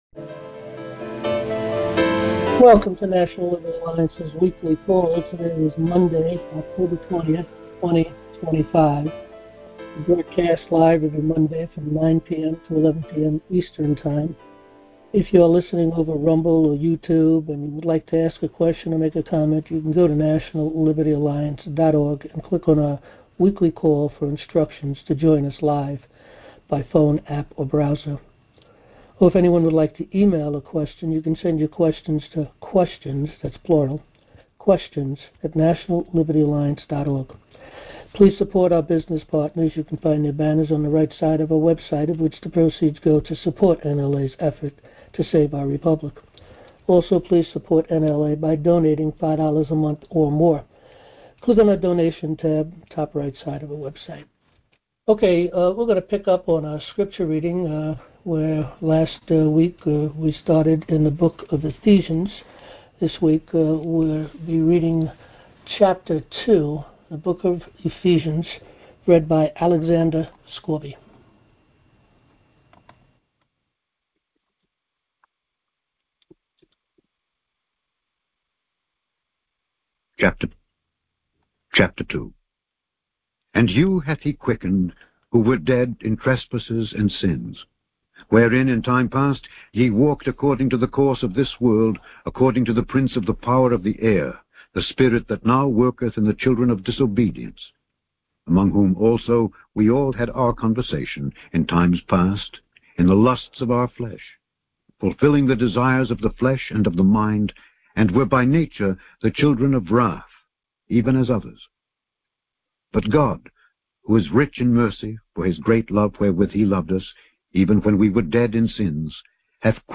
Monday - 9 PM-11PM Eastern time - Join National Liberty Alliance's Open Forum and weekly news and updates on NLA's advancements in the courts every Monday night.